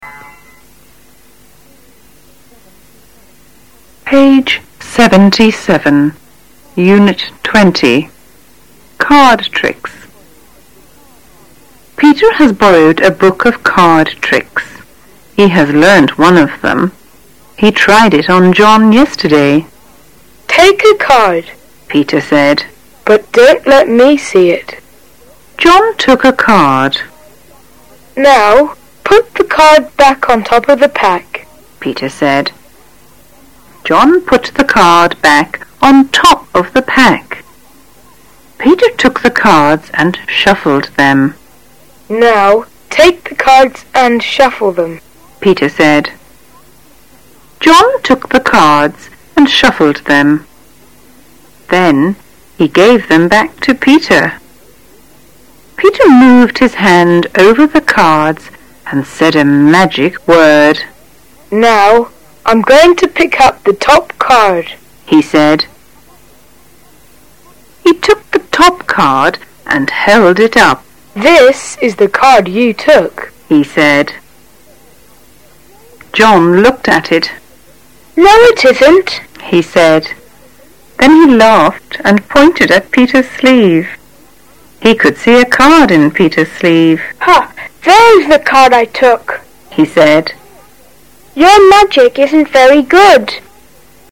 下面是六年级英语听力课文的免费下载链接，是济南奥数网小编在济南外国语小学开元国际分校获取得，本听力材料读音清晰，语速适中，非常适合六年级学生练习英语听力。